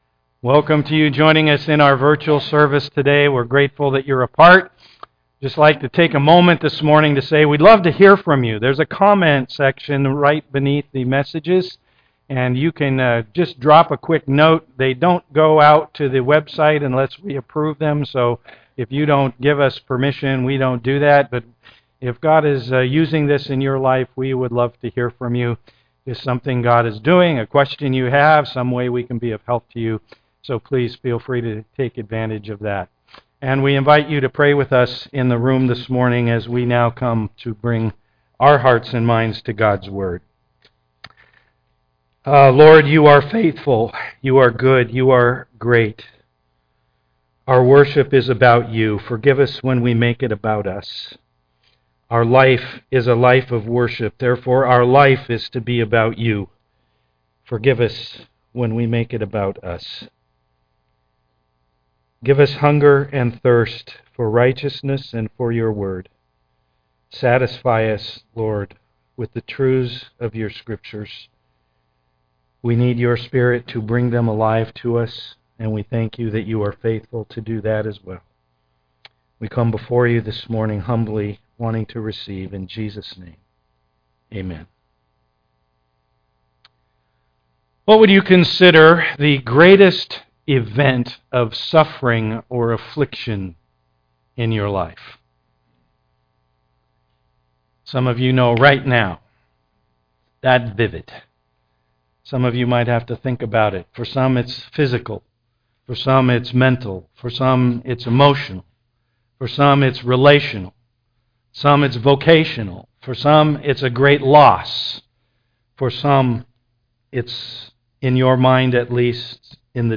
1 Thessalonians 3:1-11 Service Type: am worship What does the suffering of others inspire in you?